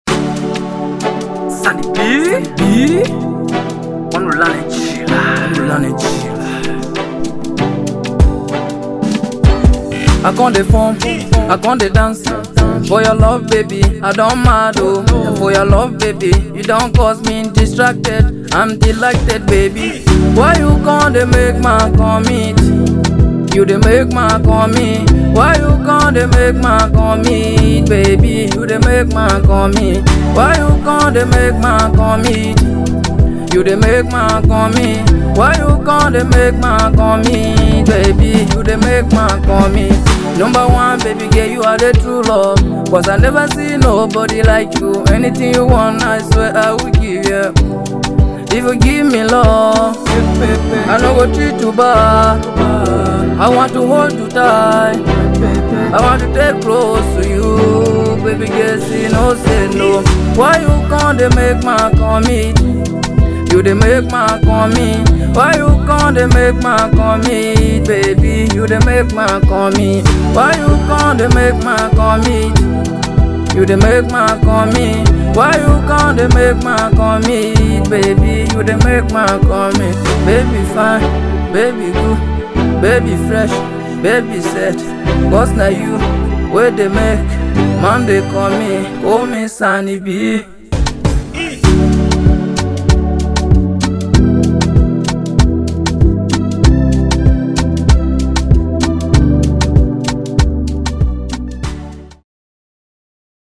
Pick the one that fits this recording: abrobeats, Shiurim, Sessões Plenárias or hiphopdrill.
abrobeats